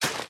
minecraft / sounds / item / hoe / till2.ogg